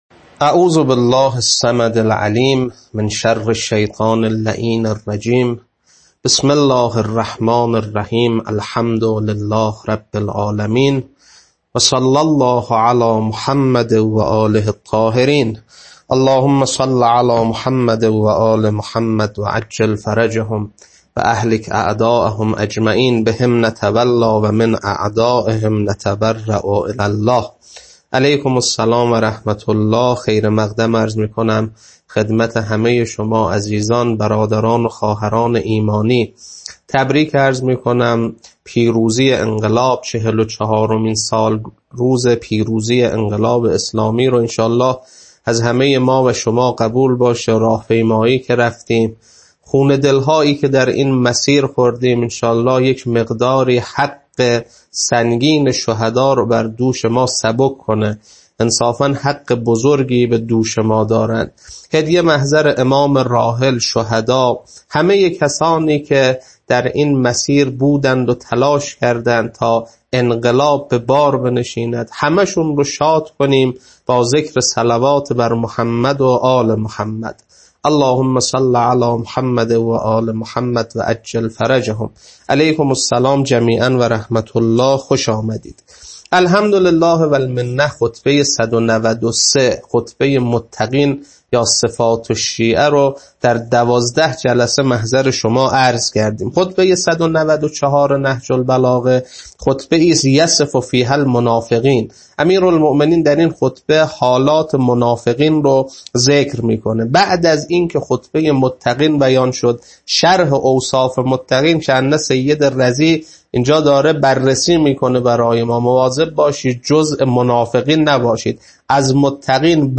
خطبه 194.mp3